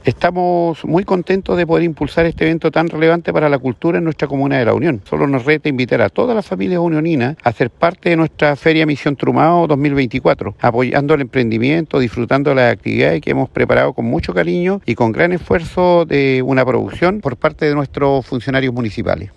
Audio-alcalde-Andrés-Reinoso-Feria-Misión-Trumao.mp3